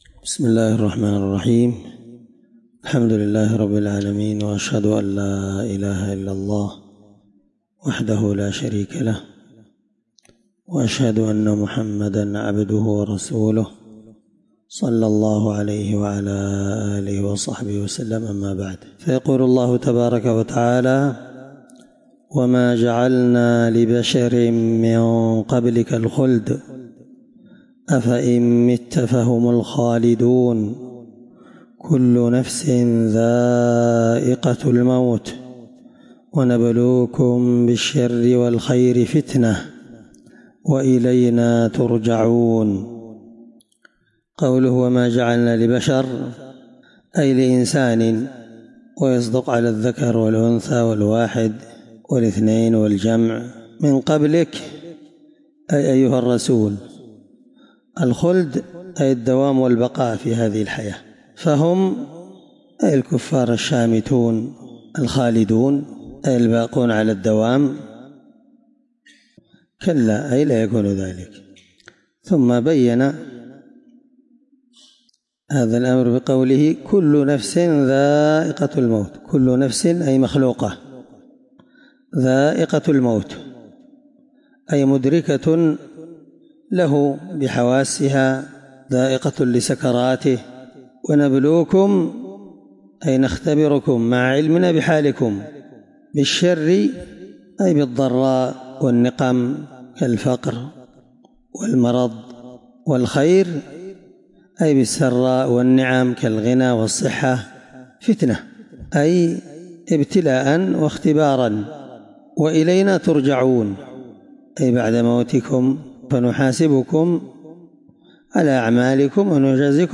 الدرس12تفسير آية (34-35) من سورة الأنبياء